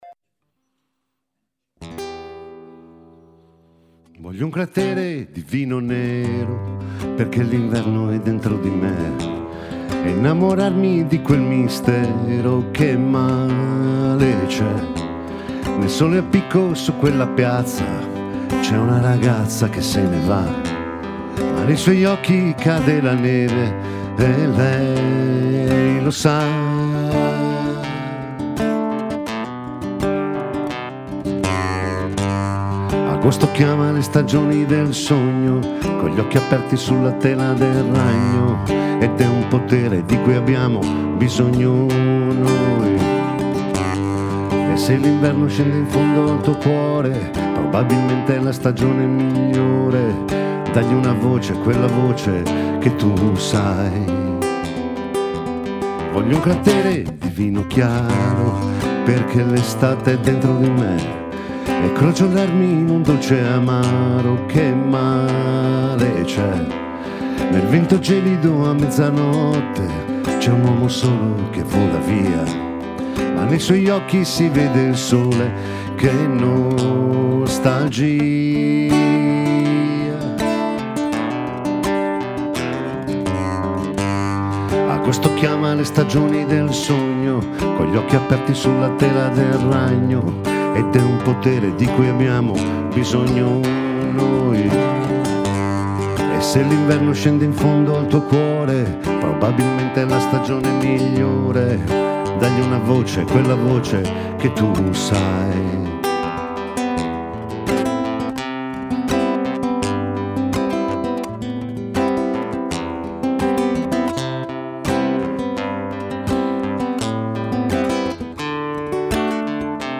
canzone d’autore